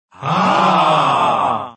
AoE2 Taunt FR 07 - ahh